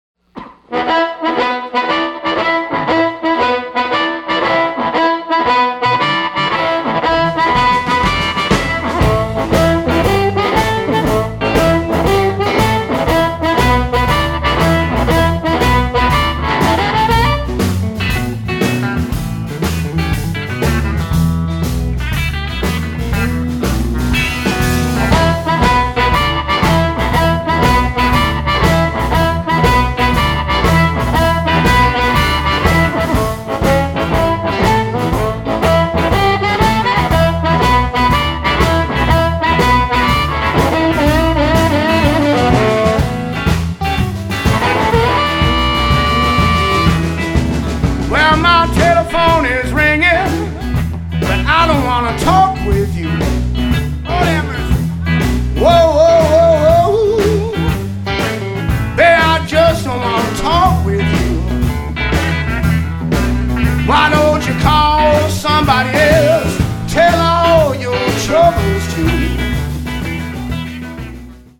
It's a fast paced show